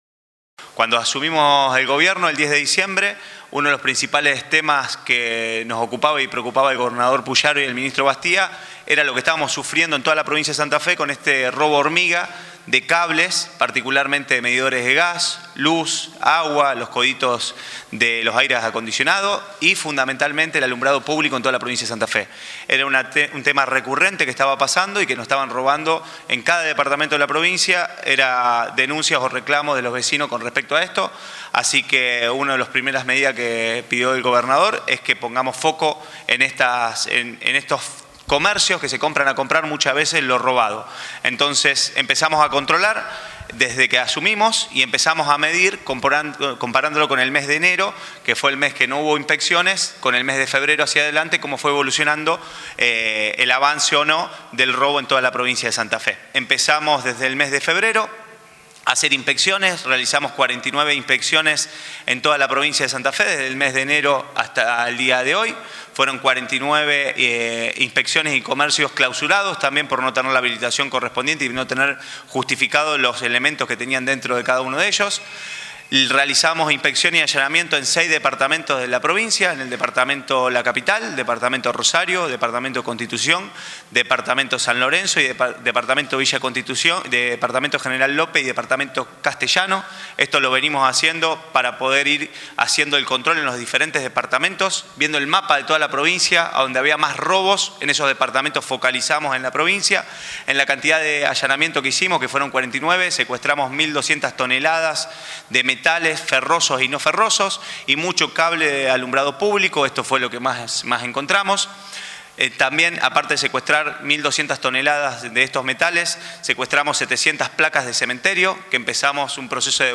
Para dar a conocer más detalles de los procedimientos, el secretario de Gestión de Registros Provinciales, Matías Figueroa Escauriza, acompañado del director de Desarmaderos, Gerardo Rodríguez, brindó este lunes una conferencia de prensa en el Auditorio de Casa de Gobierno.
Declaraciones de Figueroa Escauriza